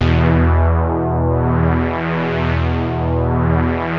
JUP.8 E3   2.wav